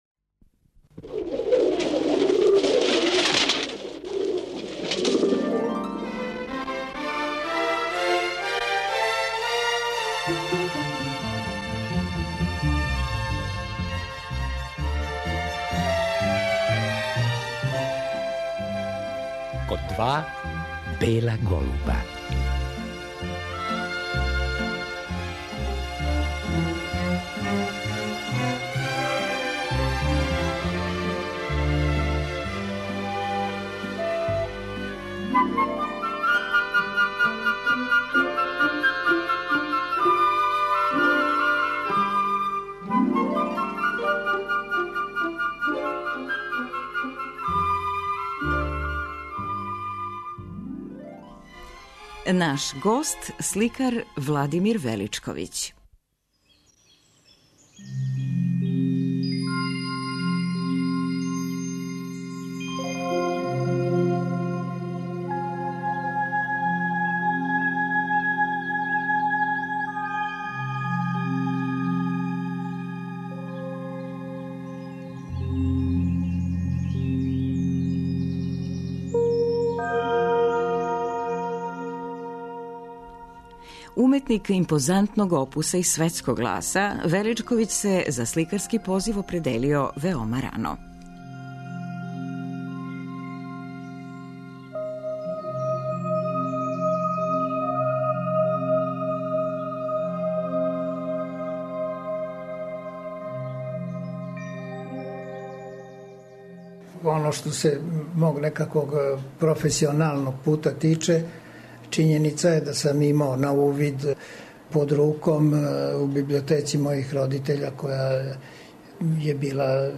Поводом изложбе цртежа Владимира Величковића у Галерији Графичког колектива, поново слушамо емисију у којој је овај сликар, уметник светског гласа, био наш гост.